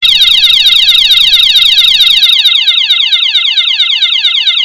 5-Emergency Sound.wav